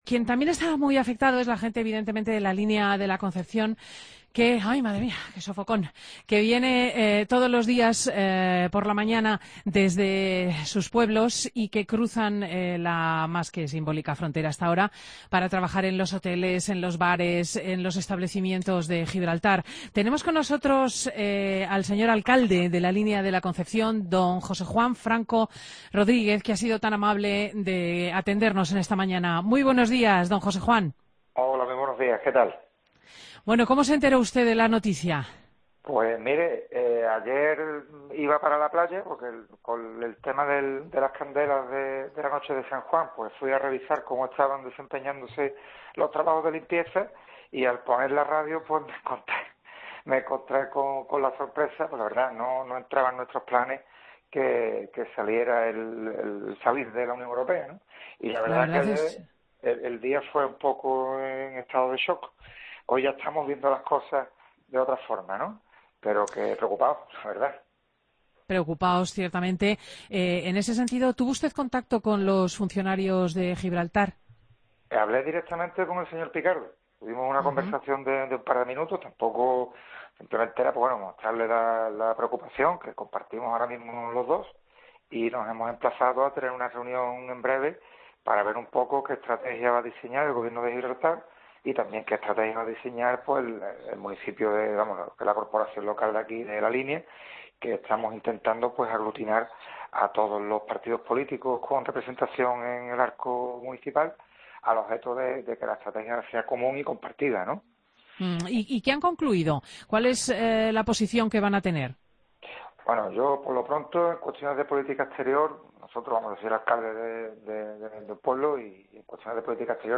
Entrevista al alcalde de La Línea de la Concepción, José Juan Franco Rodríguez